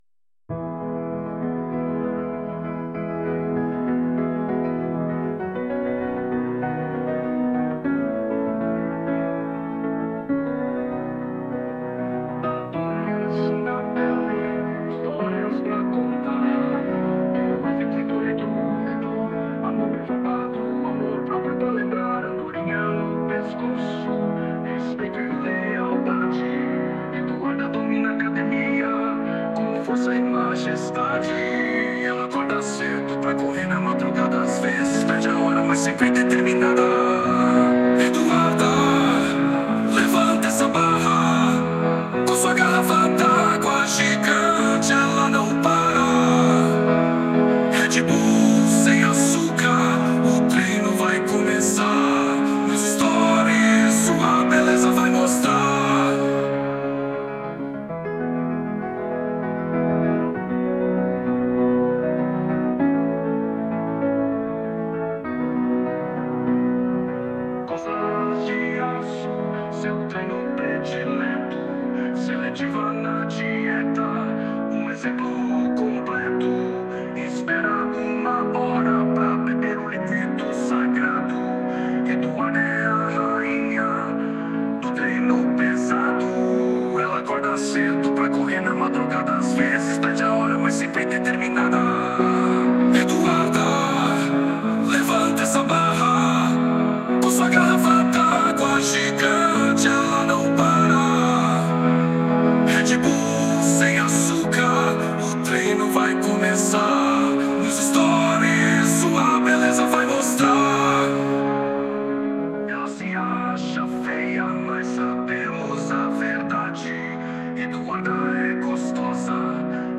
Versão Piano 1